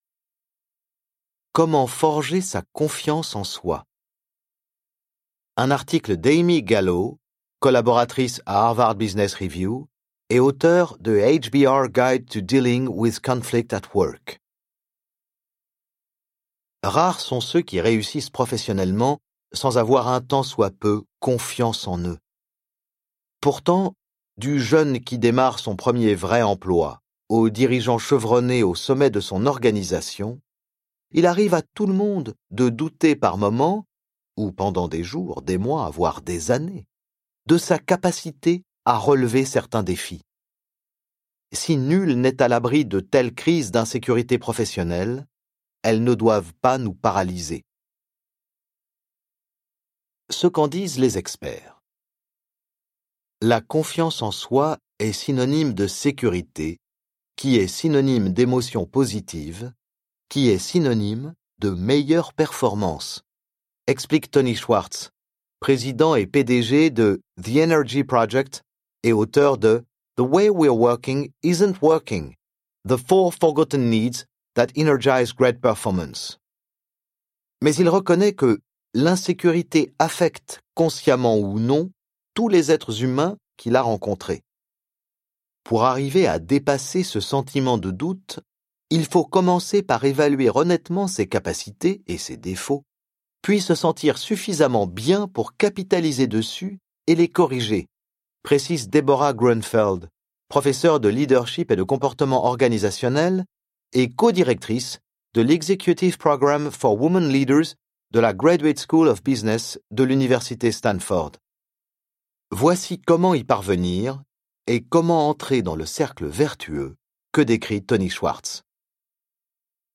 Ce livre audio fait partie de la collection Les Bienfaits de l'intelligence émotionnelle dans la vie professionnelle .